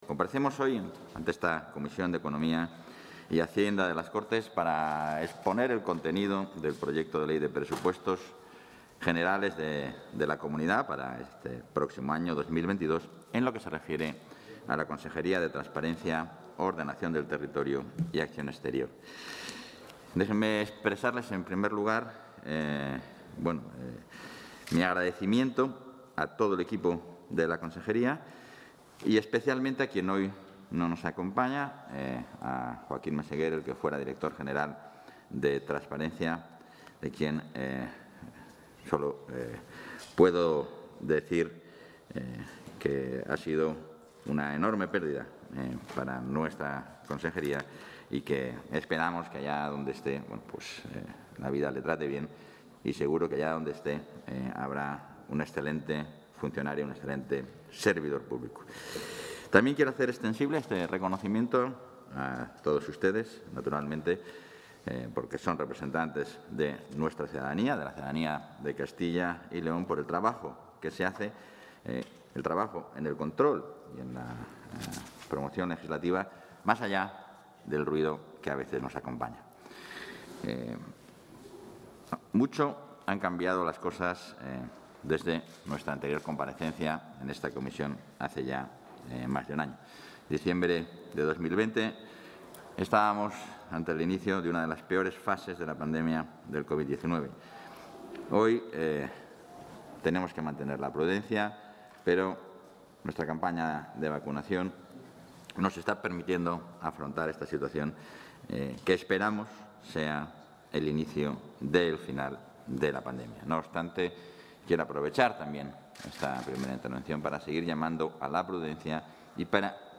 Comparecencia consejero Transparencia.